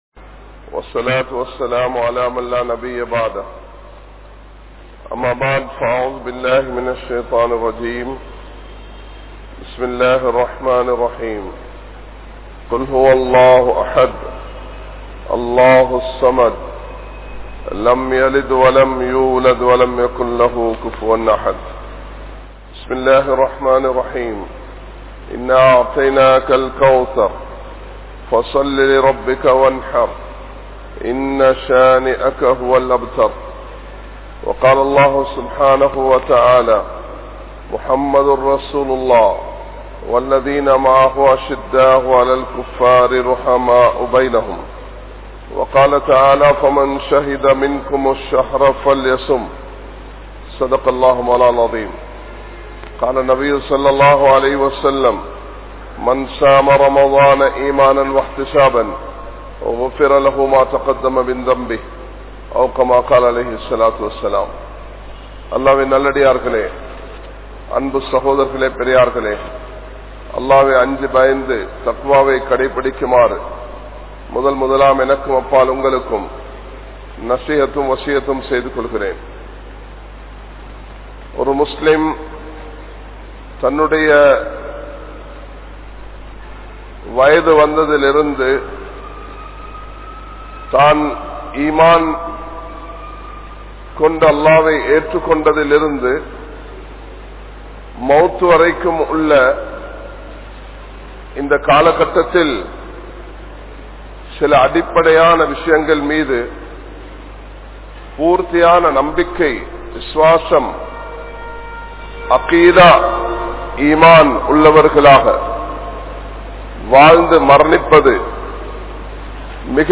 Pirachchinaihal Padatheerhal Ottrumaiyaaha Vaalungal (பிரச்சினை படாதீர்கள் ஒற்றுமையாக வாழுங்கள்) | Audio Bayans | All Ceylon Muslim Youth Community | Addalaichenai
Kandy, Line Jumua Masjith